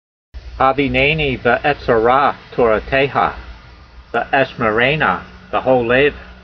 Sound (Psalm 119:34) Transliteration: hav ee'nay nee ve 'etse rah to ra tey ha , ve ' e shme' rey' nah ve ' hol - layv Vocabulary Guide: Cause me to understand so I will guard your law (torah), and I will observe it with my whole heart . Translation: Cause me to understand so I will guard your law (torah), and I will observe it with my whole heart.